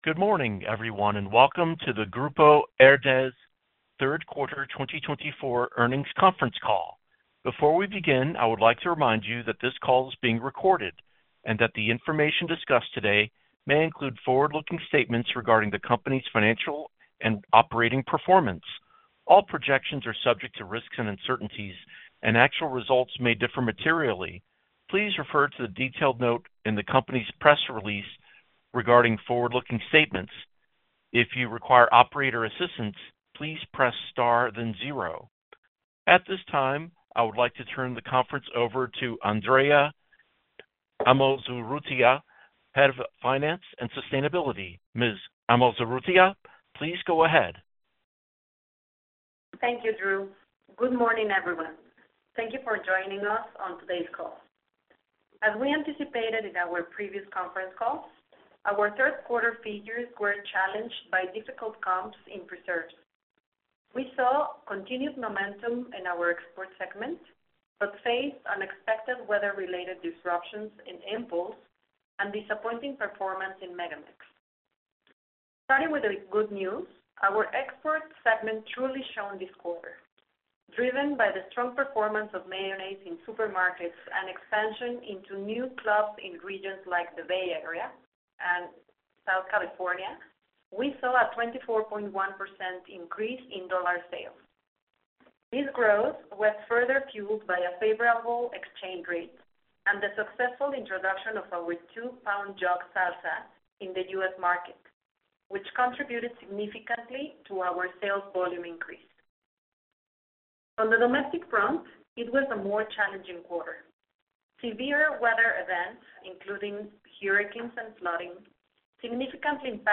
Quarterly Conference Call Audio